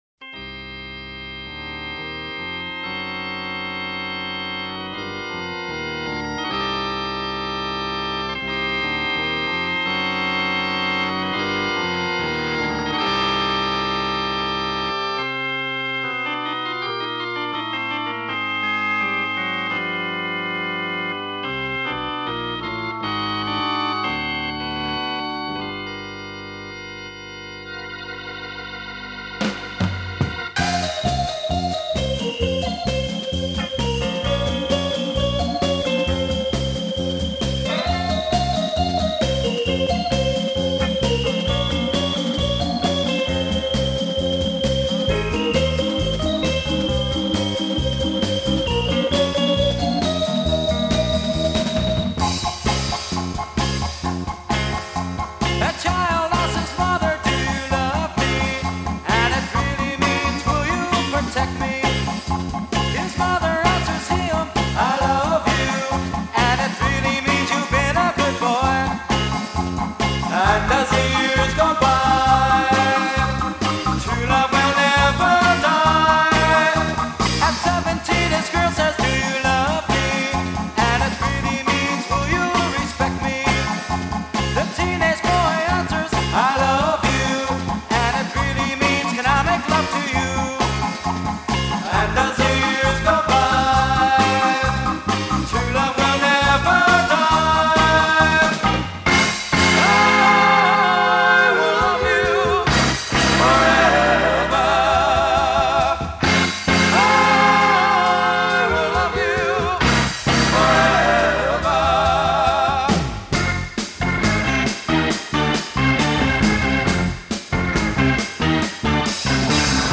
It’s got mad hooks too. Plus the intro is super scary.